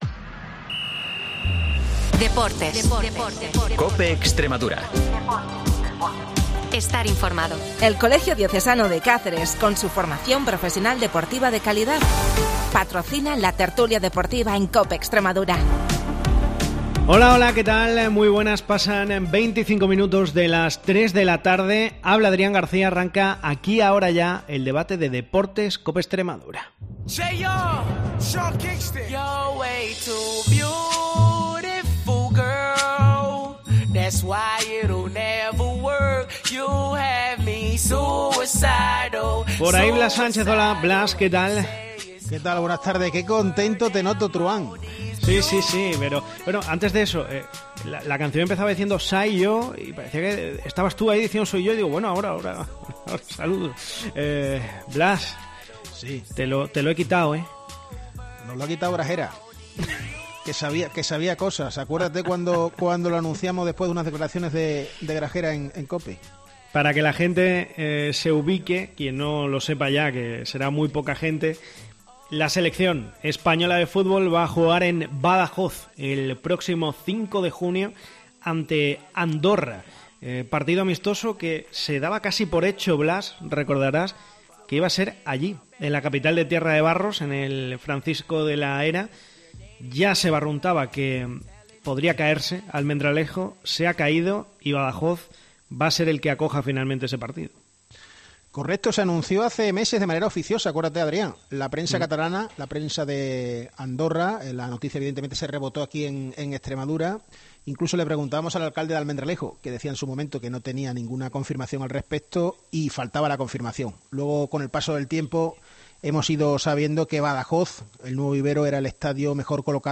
El debate de deportes de COPE Extremadura